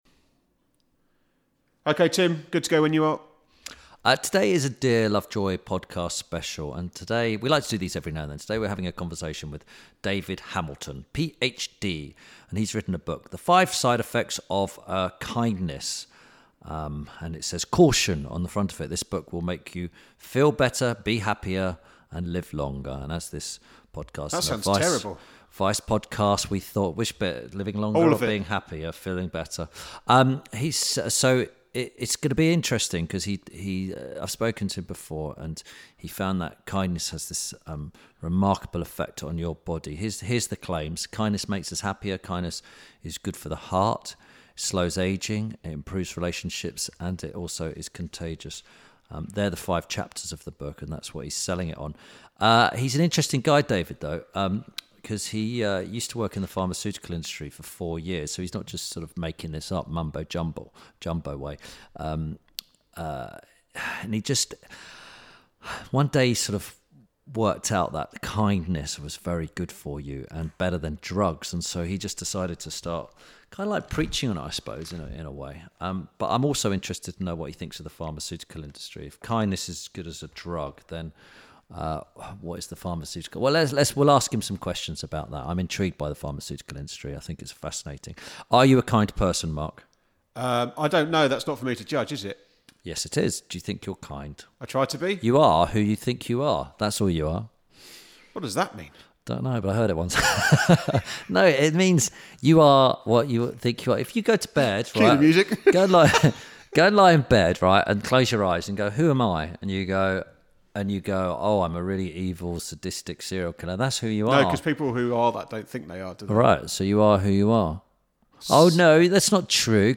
Kindness Cures Wrinkles - INTERVIEW SPECIAL.